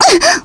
Aisha-Vox_Damage_kr_01.wav